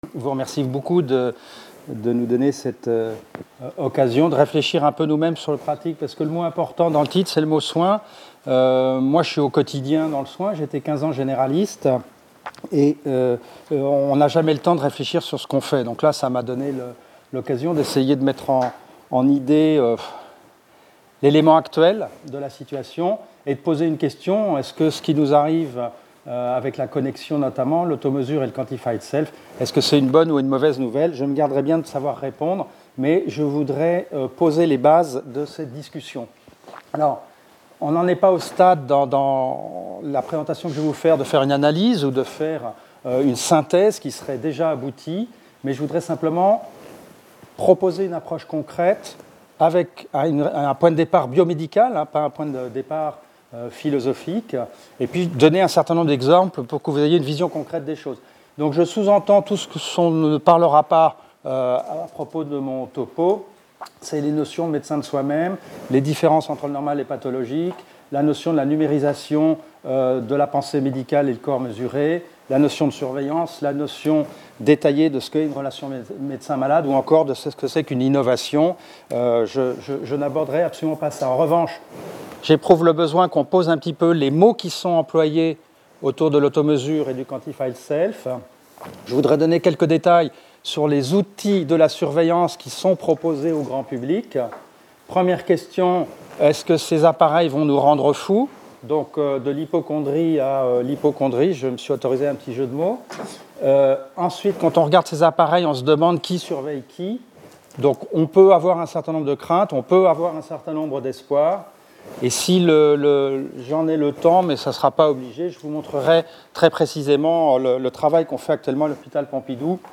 Séminaire interdisciplinaire organisé à l’Institut Français de l’Éducation de l’École Normale Supérieure de Lyon.